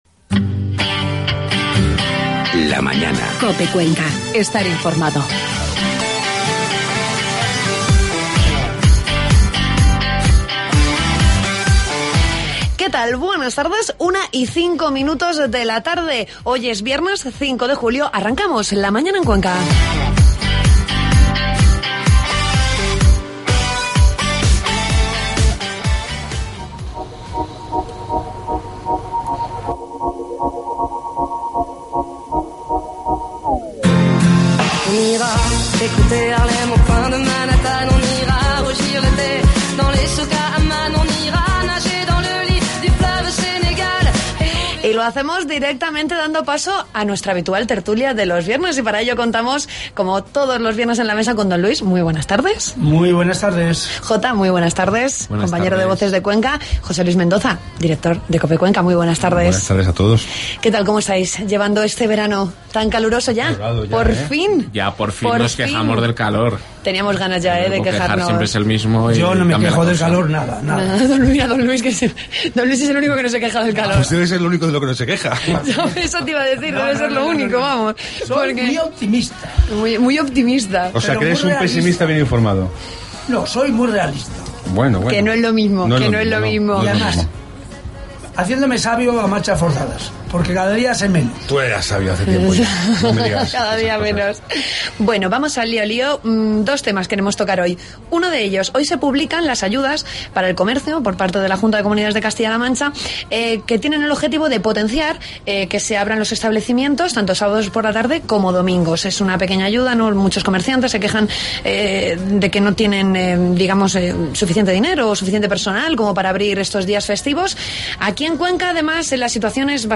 Tertulia COPE Cuenca.